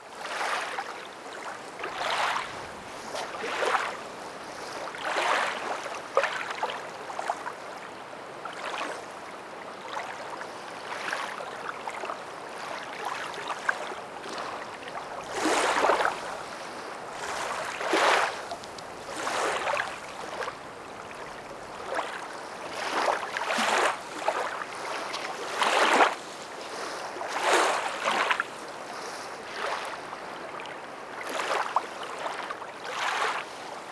LAKE LAP 00R.wav